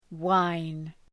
Προφορά
{waın}